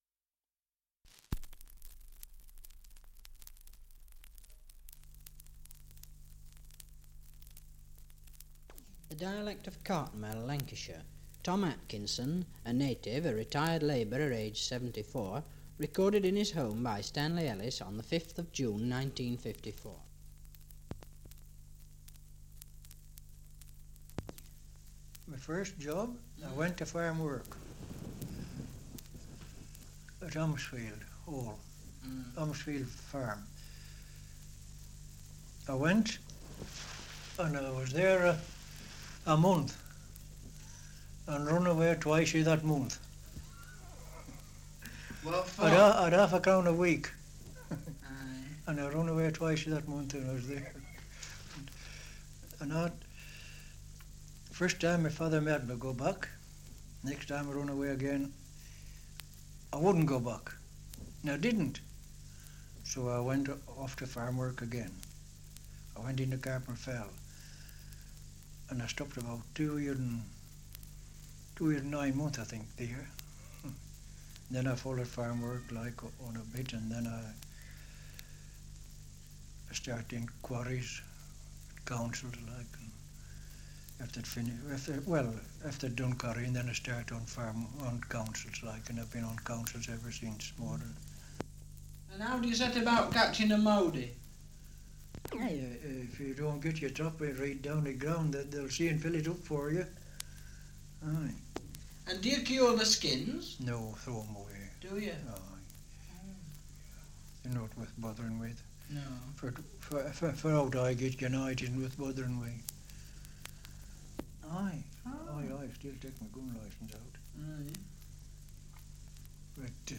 Survey of English Dialects recording in Cartmel, Lancashire
78 r.p.m., cellulose nitrate on aluminium